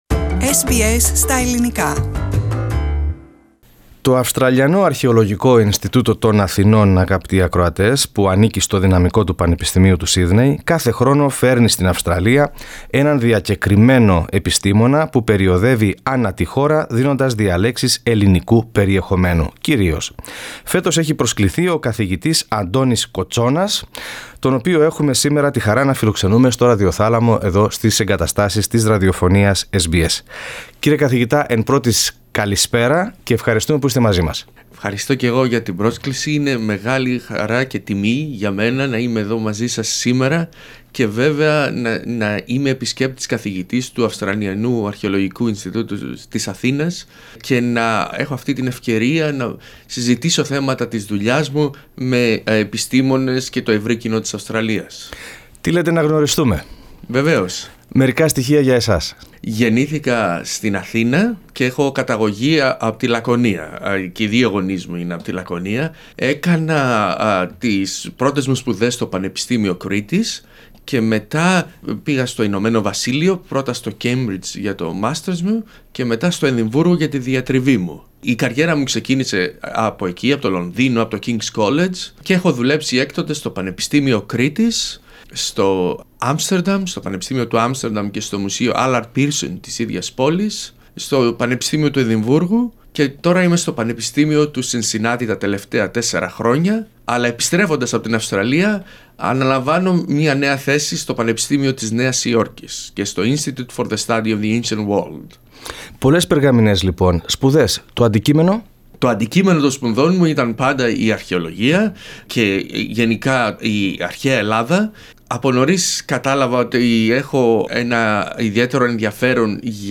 Source: SBS Greek